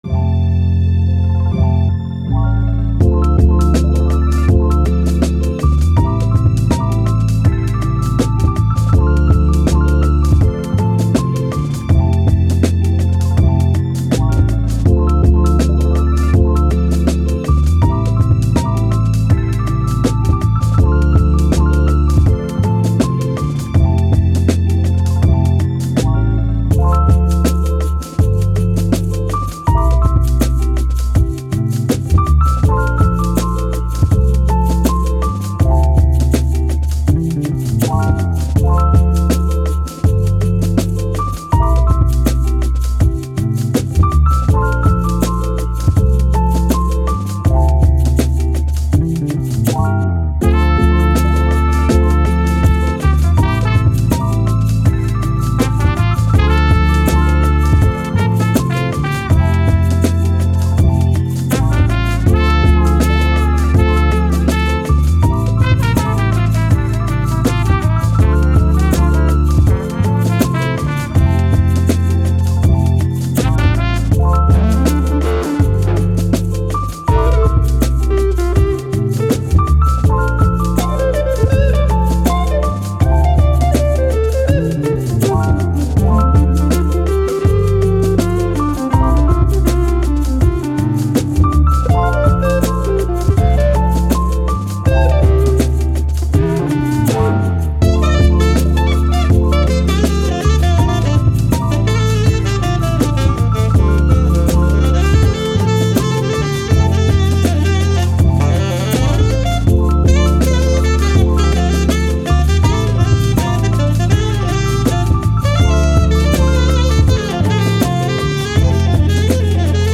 Hip Hop, Soul, Saxophone, Jazz, Positive